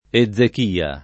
Ezechia [ e zz ek & a ]